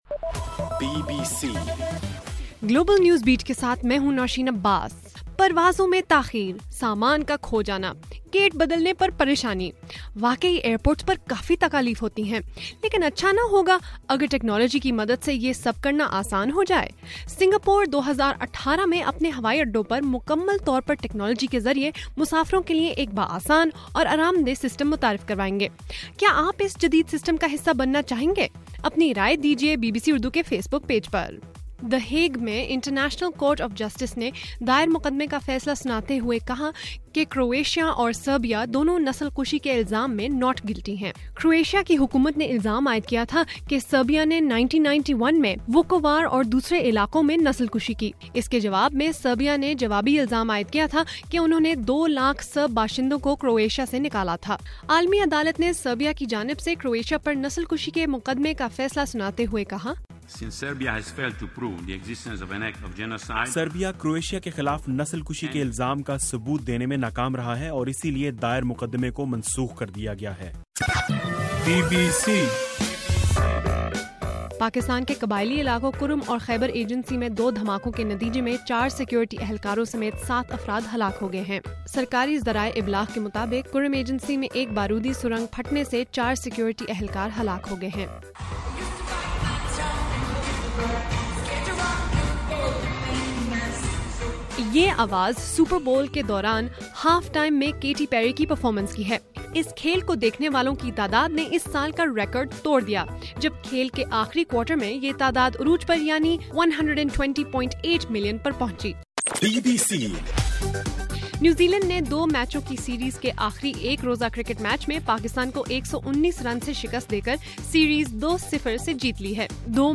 فروری 3: رات 8 بجے کا گلوبل نیوز بیٹ بُلیٹن